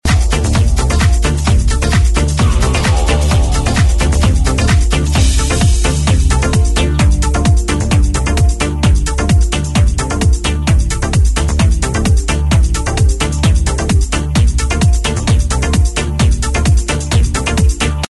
mixing in the background.